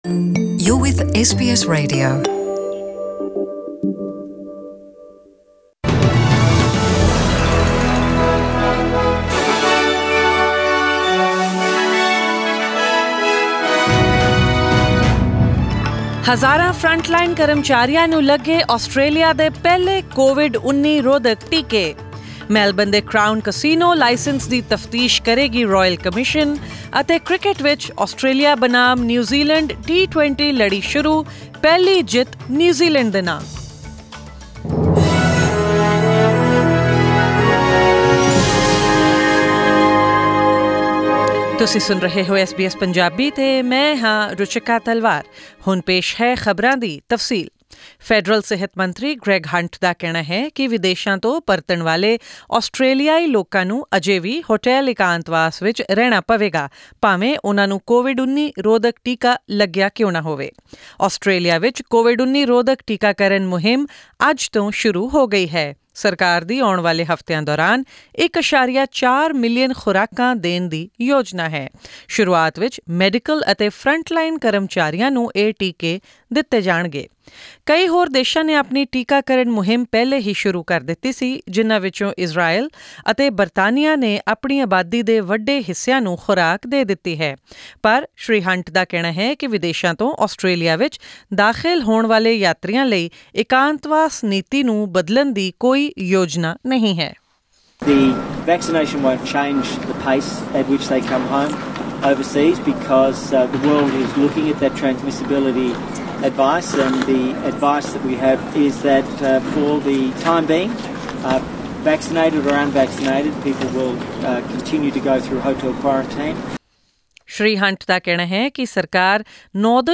Australia's vaccination rollout has begun, with the government planning to administer 1.4 million doses during the first phase over coming weeks. Tune into the bulletin tonight for more local and international news, updates on sports, forex rates and the weather forecast for tomorrow.